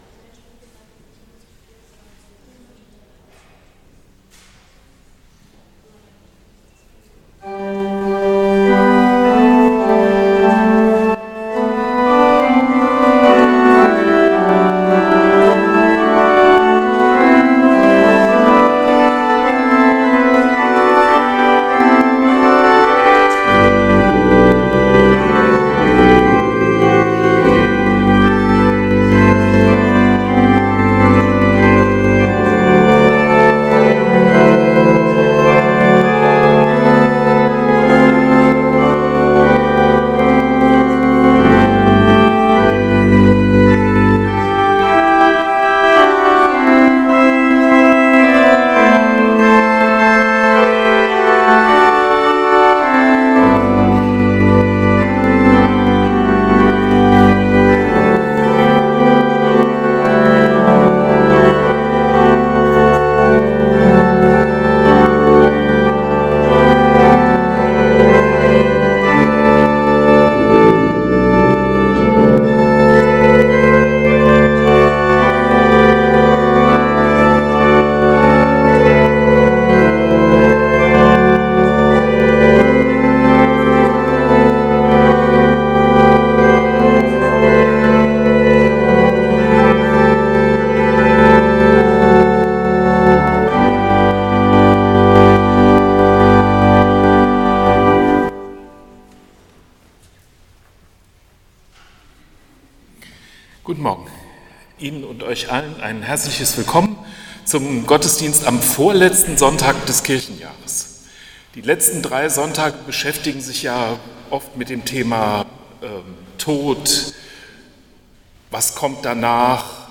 Gottesdienst vom 16.11.2025 als Audio-Podcast Liebe Gemeinde, herzlich Willkommen zum Gottesdienst am 16. November 2025 in der Martinskirche Nierstein als Audio-Podcast.